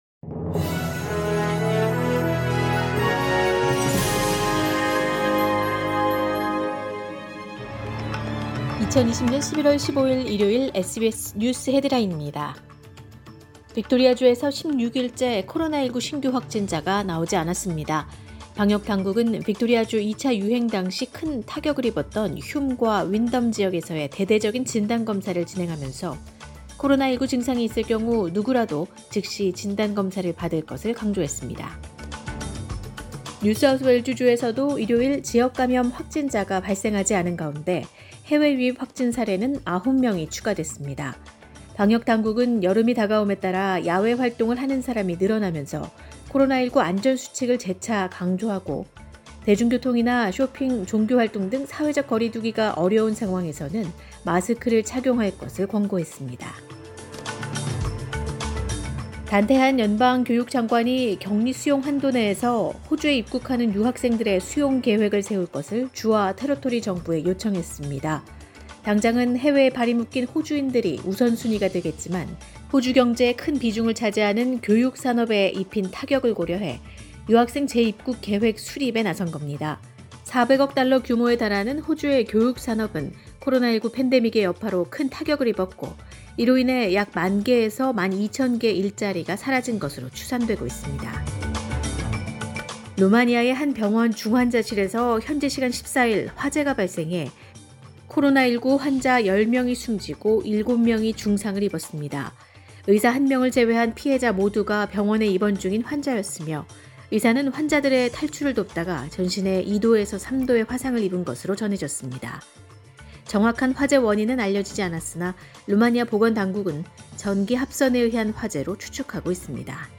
SBS News Headlines…2020년 11월 15일 오전 주요 뉴스
2020년 11월 15일 일요일 오전의 SBS 뉴스 헤드라인입니다.